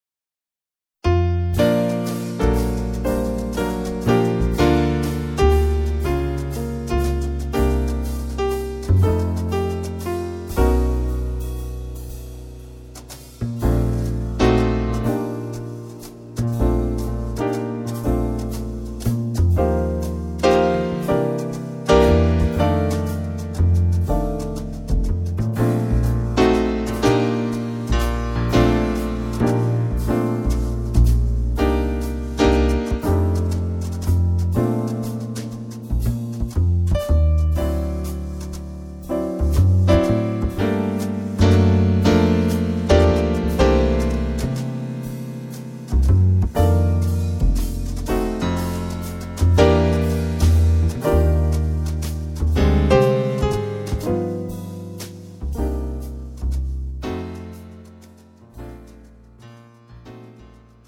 고음질 반주